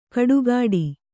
♪ kaḍugāḍi